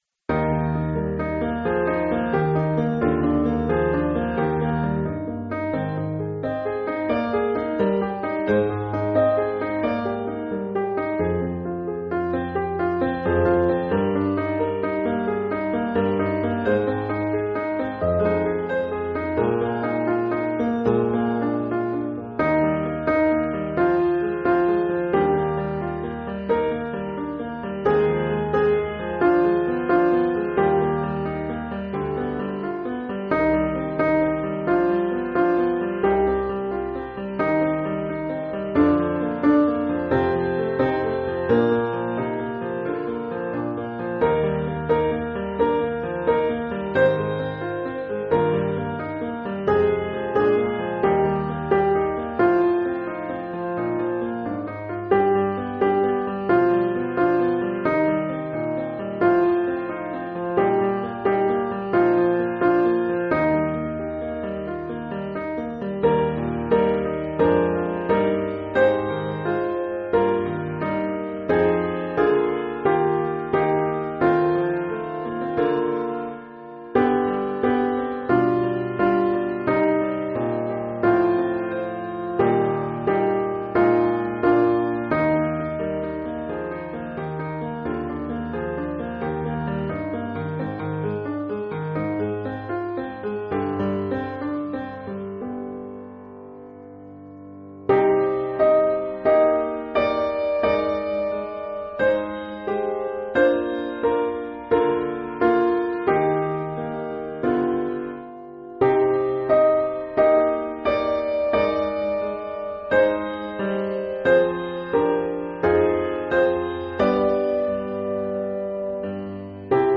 Like a River Glorious – Soprano